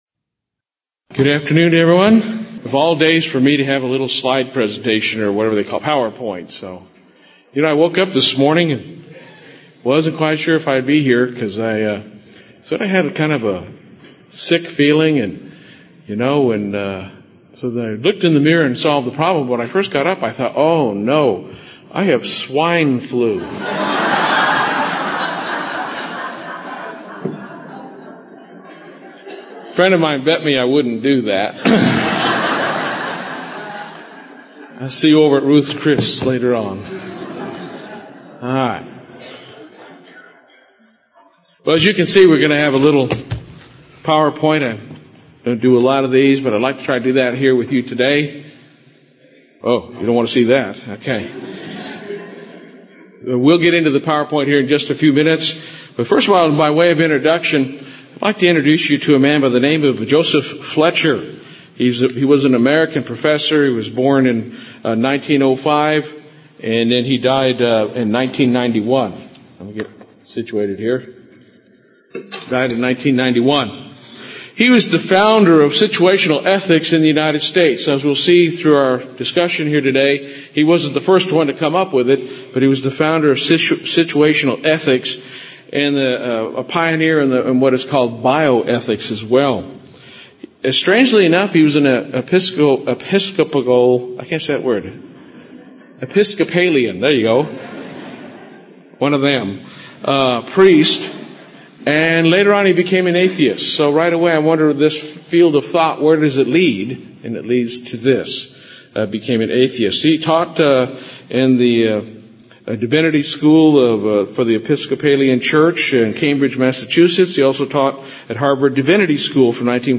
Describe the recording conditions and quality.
Kerrville 2009 Feast of Tabernacles. Do situational ethics have a place in your life?